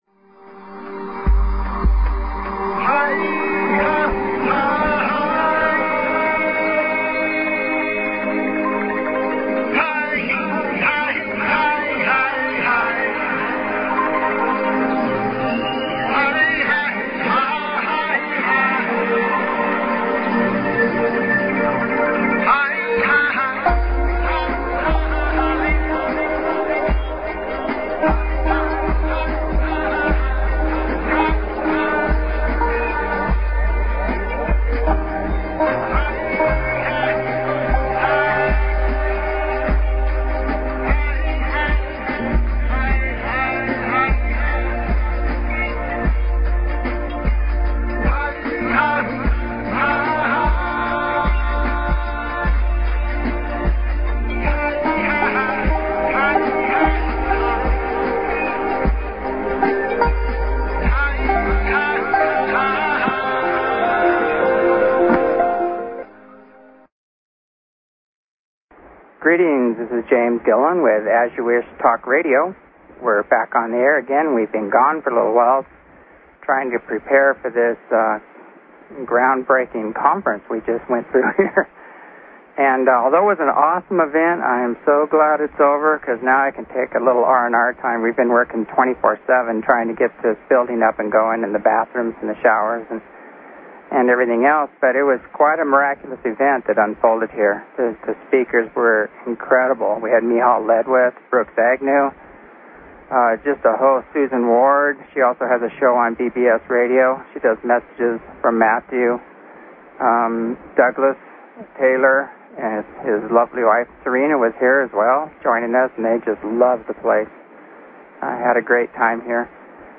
Talk Show Episode, Audio Podcast, As_You_Wish_Talk_Radio and Courtesy of BBS Radio on , show guests , about , categorized as
CONTACT HAS BEGUN DISCUSSION, SKYWATCH LIVE INTERVIEWS
As you Wish Talk Radio, cutting edge authors, healers & scientists broadcasted Live from the ECETI ranch, an internationally known UFO & Paranormal hot spot.